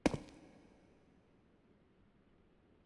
FootstepHandlerBrick3.wav